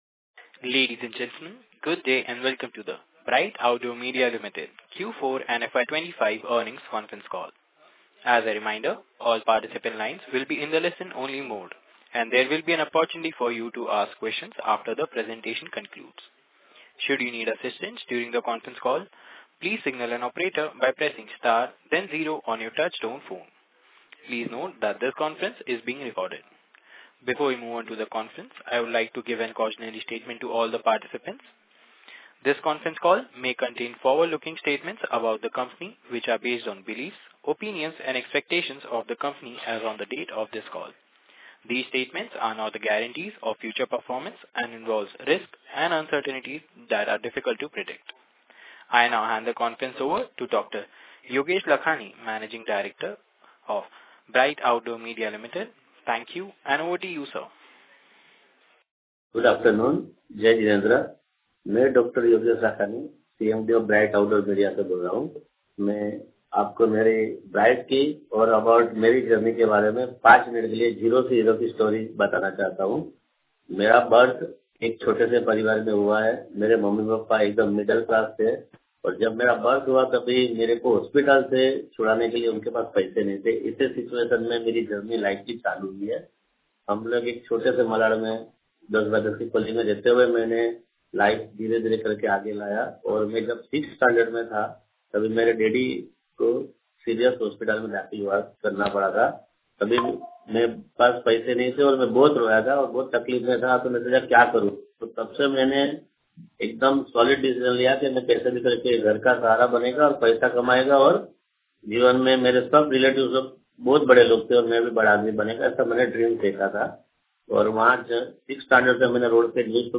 Bright Outdoor Media Limited H2 & FY '25 Earnings Conference Call